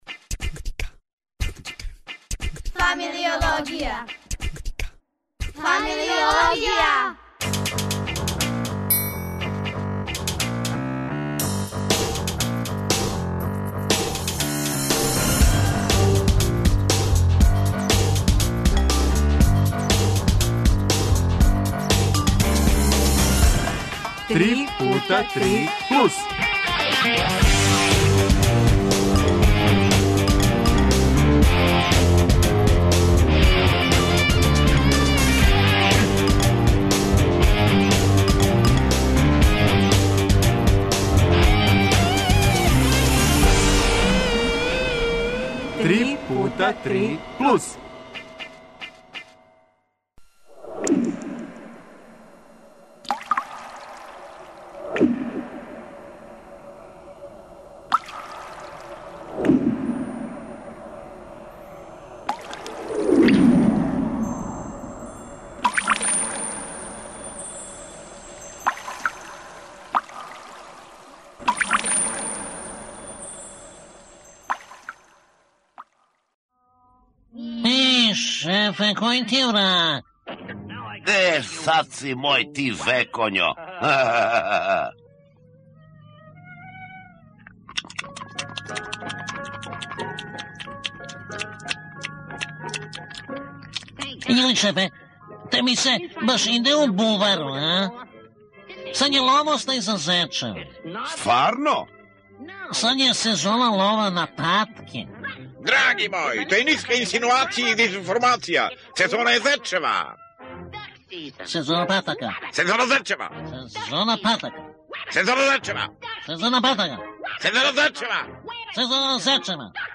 А, ту су и деца, да (му) помогну.